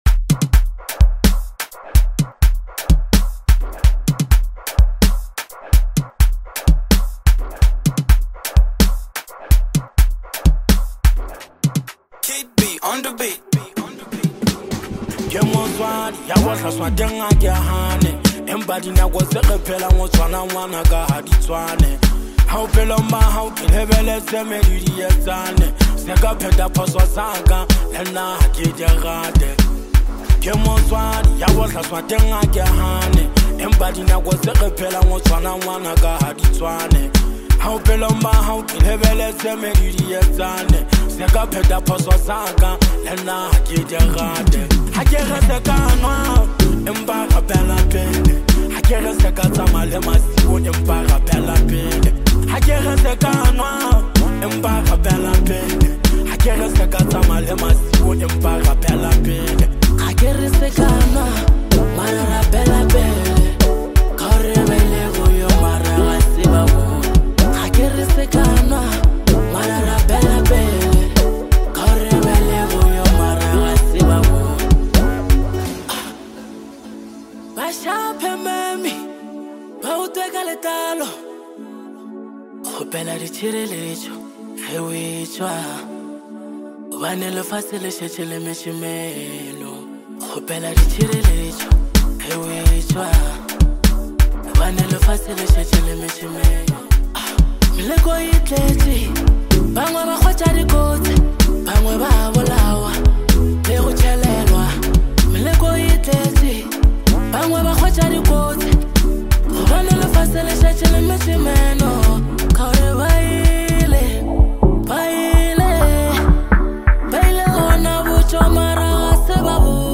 Home » DJ Mix » Hip Hop » Lekompo